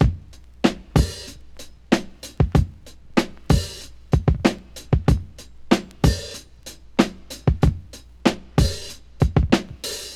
• 94 Bpm Fresh Drum Loop D# Key.wav
Free drum groove - kick tuned to the D# note. Loudest frequency: 803Hz
94-bpm-fresh-drum-loop-d-sharp-key-Ywz.wav